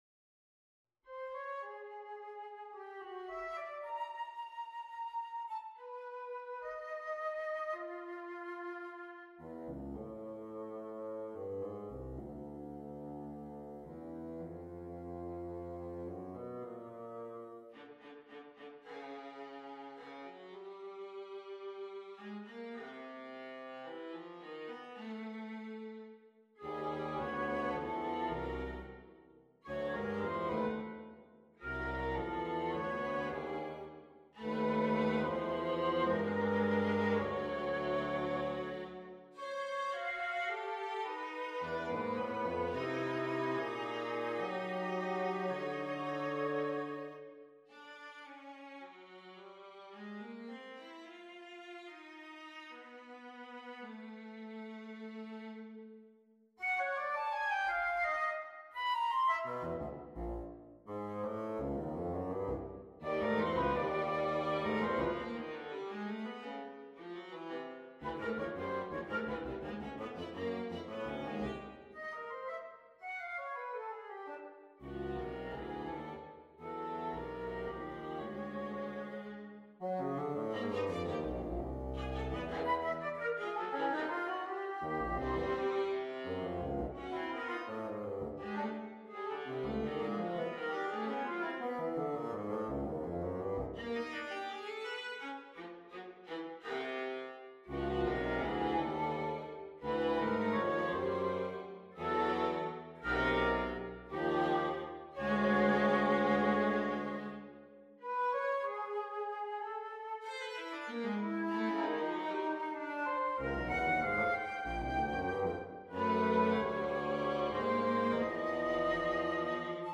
Maitrayaniya on a purpose-selected tone row Op.105 Andante con moto - Allegro molto - Presto assai - Vivace - Grave - Larghetto - Adagio - Adagietto - Tempo Quattro - Tempo Terzo - Tempo Secondo - Andante Date Duration Download 13 June 2025 12'03" Realization (.MP3) Score (.PDF) 11.0 MB 543.1 KB